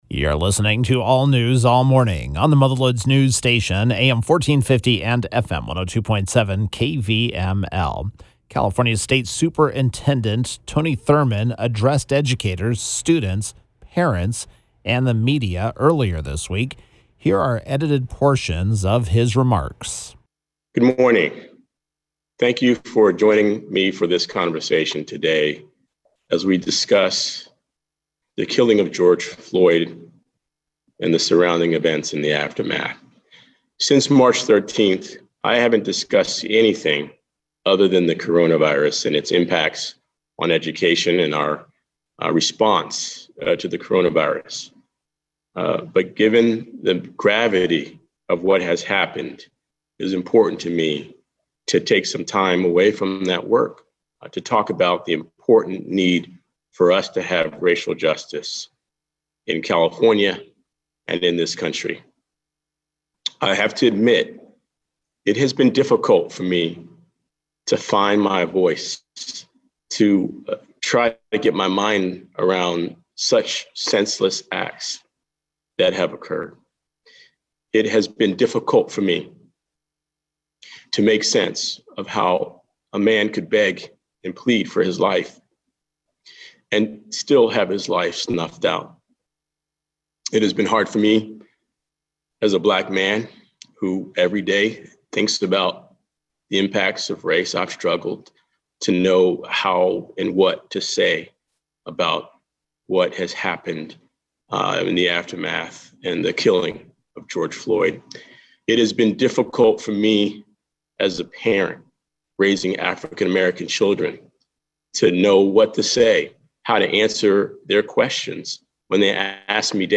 During a live stream teleconference Monday, State Schools Superindent Tony Thurmond, the only elected official in the state who is African American, announced plans to lead an effort focusing on racism in public schools as he shared emotional remarks about the killing that he said left him struggling to answer his own children’s questions as to why it happened. Here are the edited remarks of Thurmond: